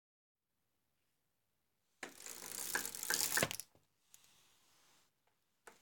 Het openen van een rolgordijn?
Jaa klopt!